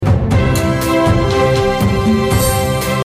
青铜熔铸_Audio.MP3